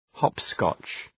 Προφορά
{‘hɒpskɒtʃ}
hopscotch.mp3